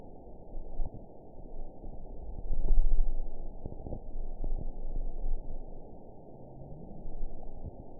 event 911184 date 02/14/22 time 00:46:26 GMT (3 years, 9 months ago) score 8.80 location TSS-AB08 detected by nrw target species NRW annotations +NRW Spectrogram: Frequency (kHz) vs. Time (s) audio not available .wav